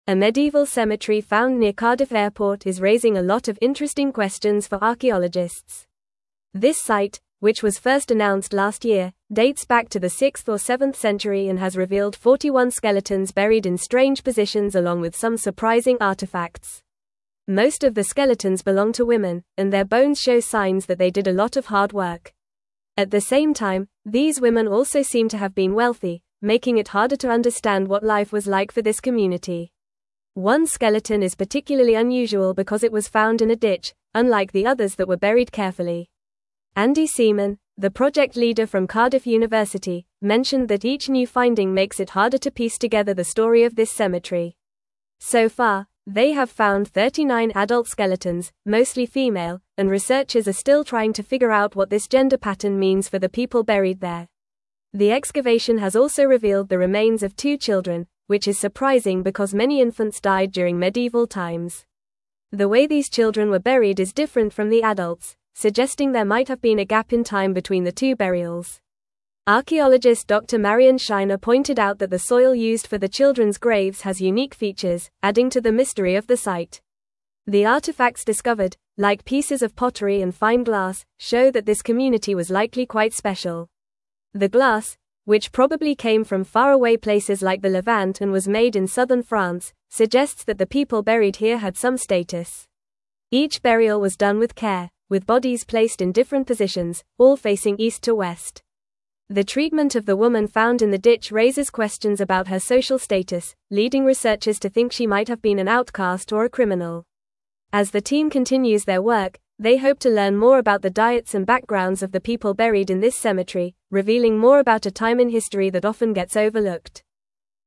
Fast
English-Newsroom-Upper-Intermediate-FAST-Reading-Medieval-Cemetery-Near-Cardiff-Airport-Unveils-Mysteries.mp3